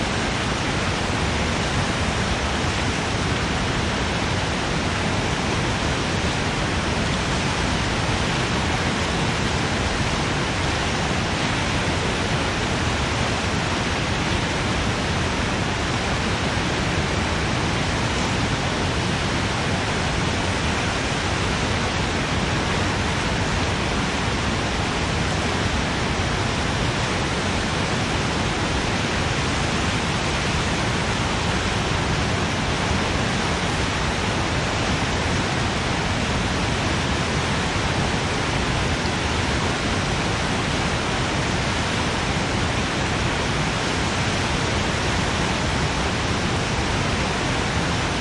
随机的" 水流激荡的隆隆声工业噪音工厂和火车我想
描述：水急流低温隆隆工业噪音工厂分机和火车我认为.flac
Tag: 湍急 水分多 隆隆声